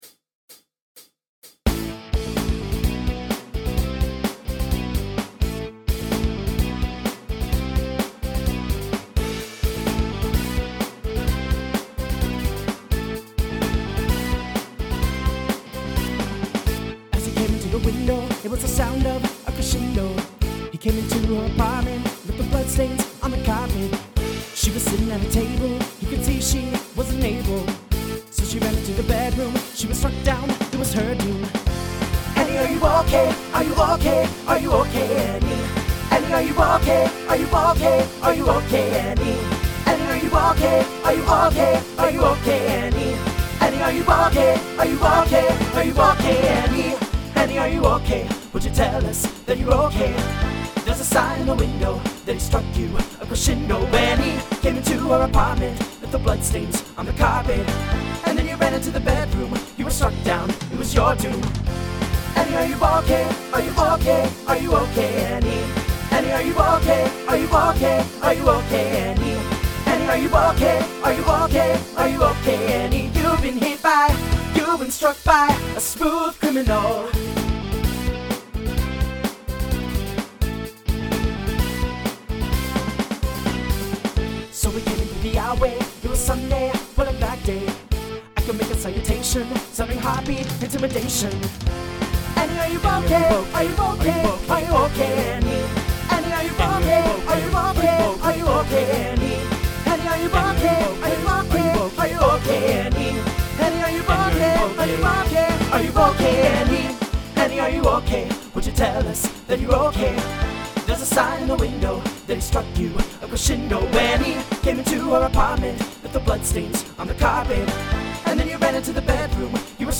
SATB